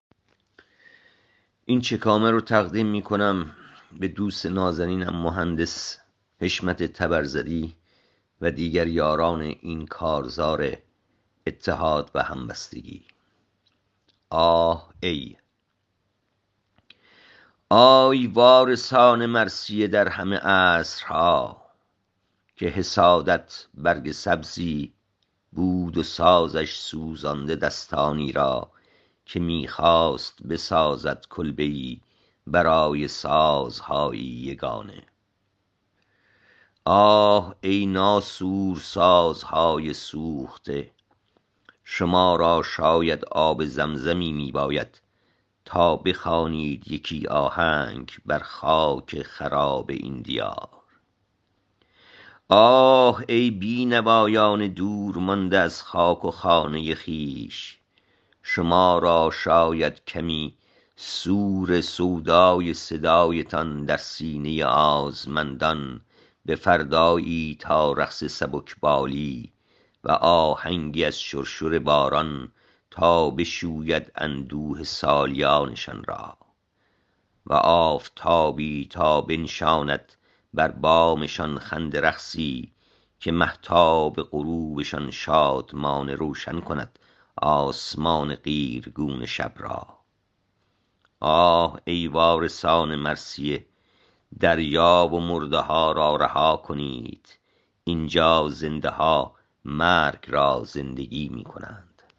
این شعر را با صدای شاعر از این‌جا بشنوید